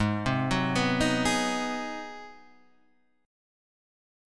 G#7#9 chord {4 3 4 4 4 4} chord